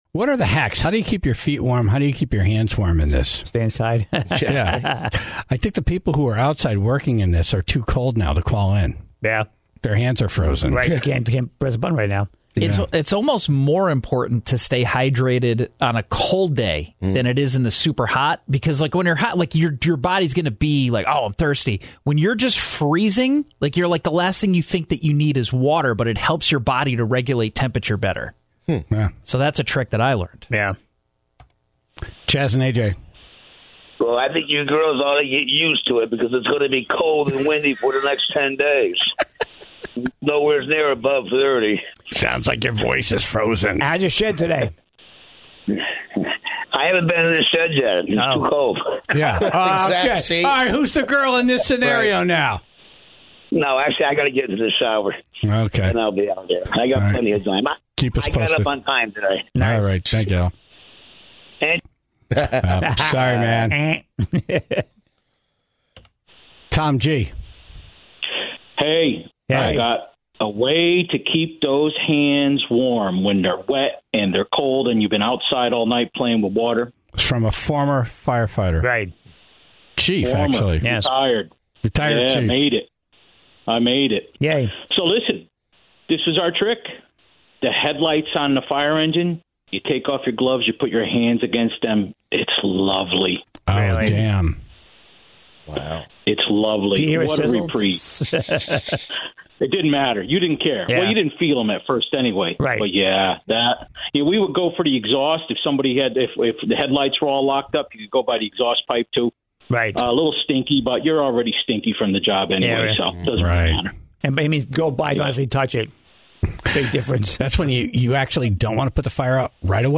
so the Tribe called in to share their experiences on the job during freezing temperatures.